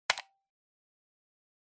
button.mp3